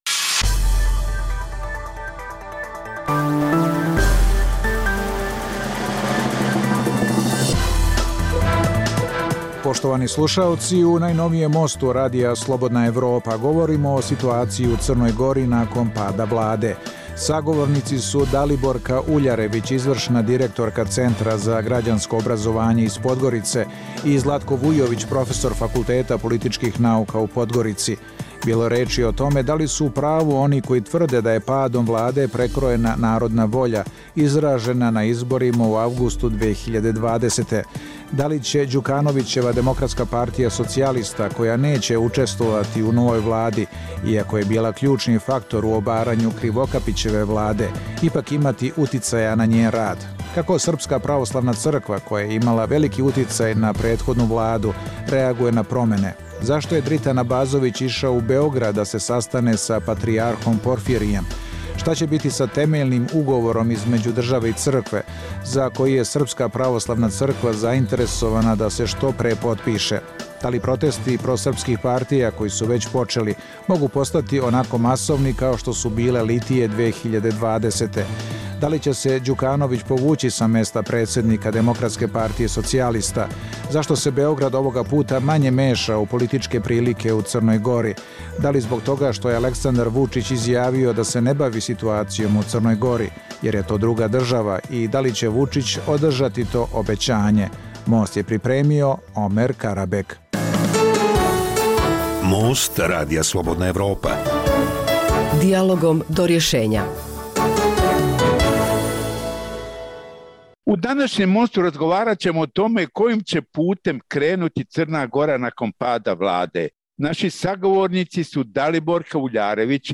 Dijaloška emisija o politici, ekonomiji i kulturi